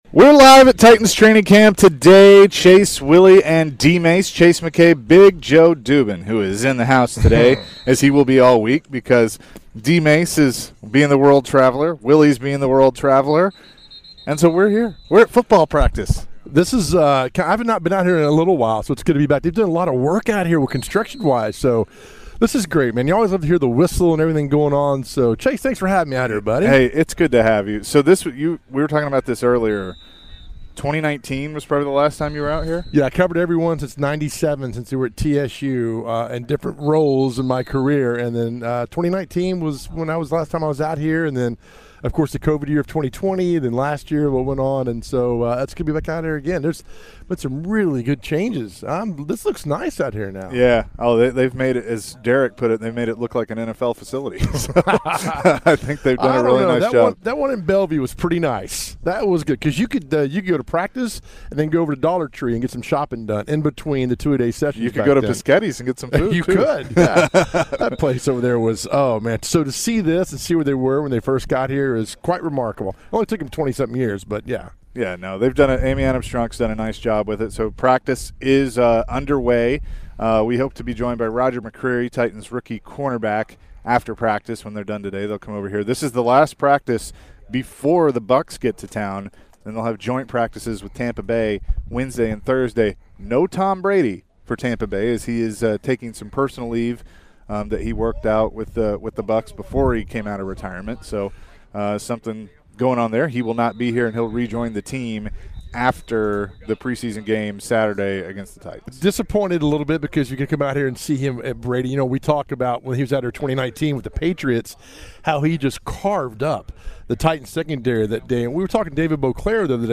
Hour 1: Live from Titans practice, Game Blitz is back (8-15-22)